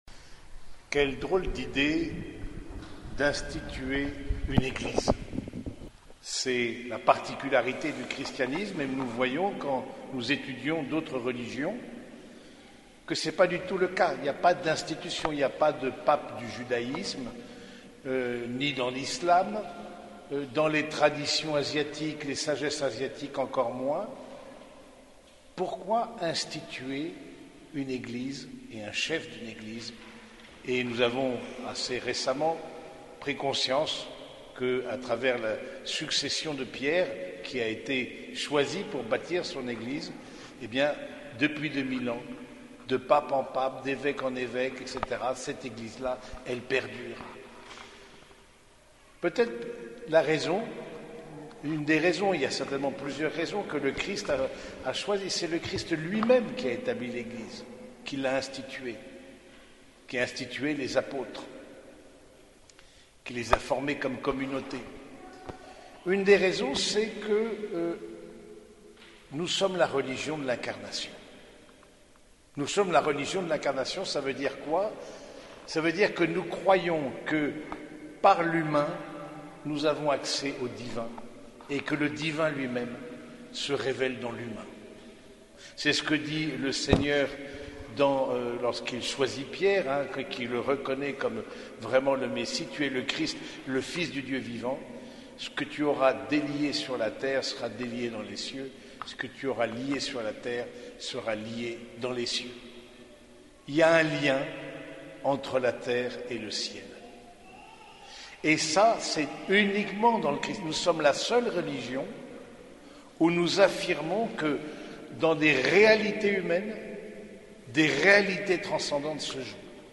Homélies dominicales - Serviteurs de Jésus et de Marie